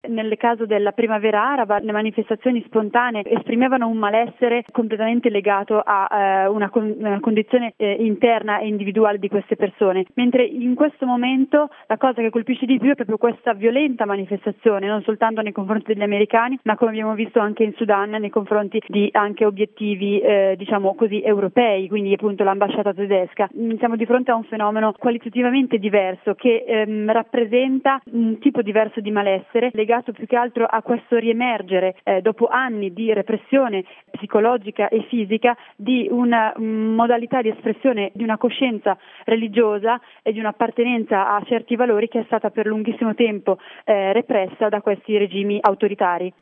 intervista
all'interno del Gr delle ore 8, sulla situazione nel mondo arabo, Radio Capital